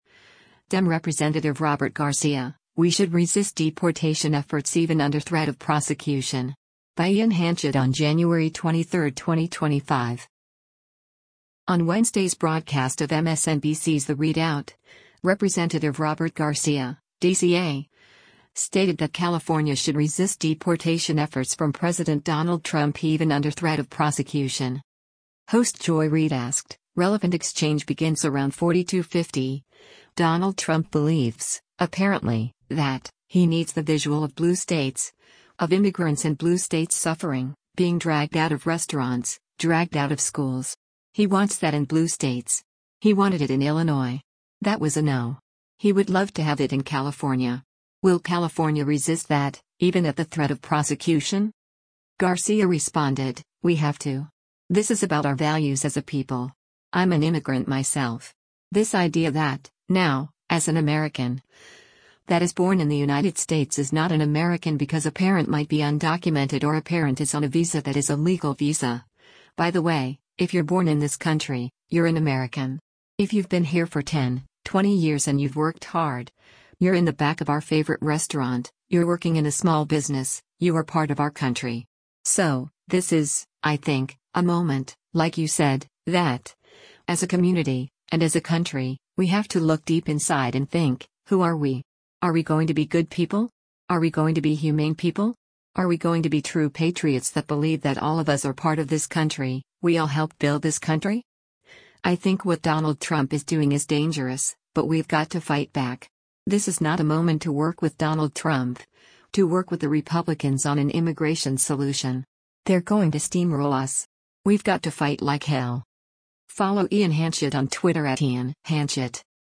On Wednesday’s broadcast of MSNBC’s “The ReidOut,” Rep. Robert Garcia (D-CA) stated that California should resist deportation efforts from President Donald Trump even under threat of prosecution.